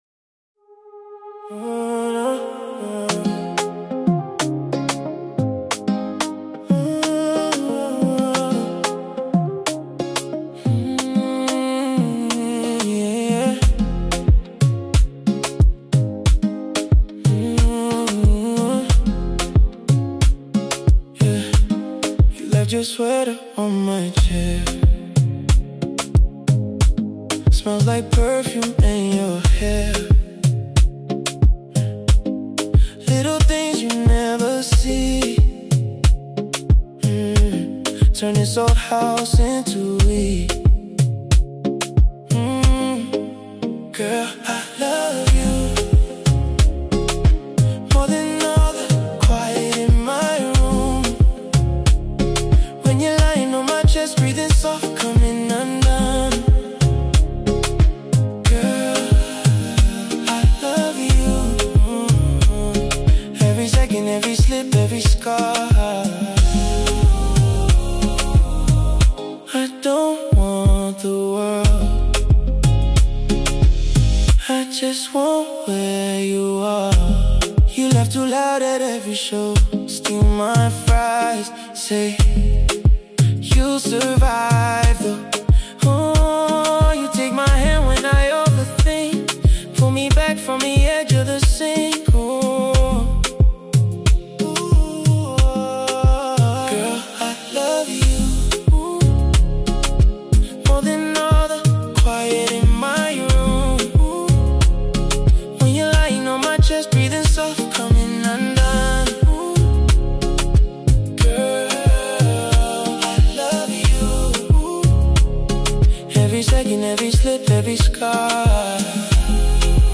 This one comes across like a quiet promise.